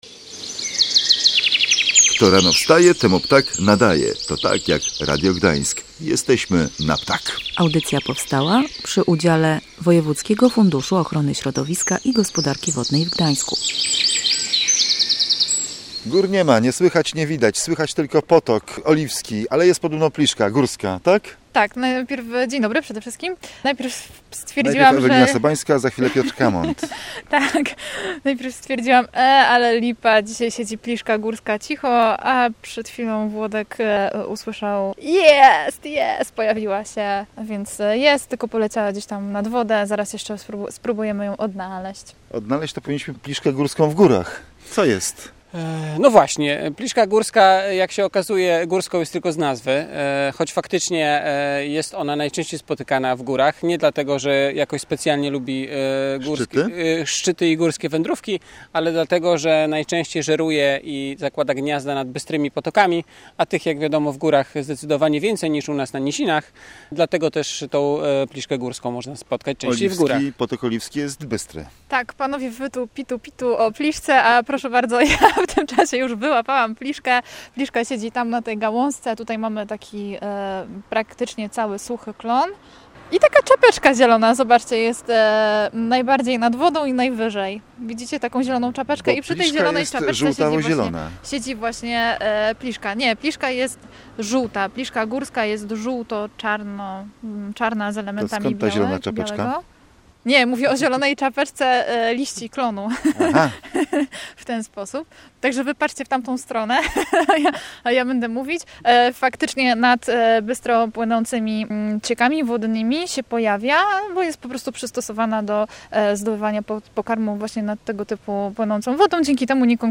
Jesteśmy nad Potokiem Oliwskim przy Kuźni Wodnej.